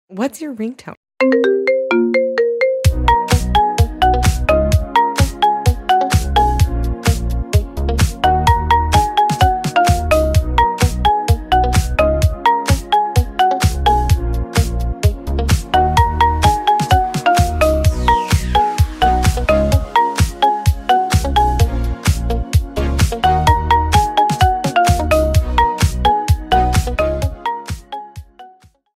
Catégorie Marimba Remix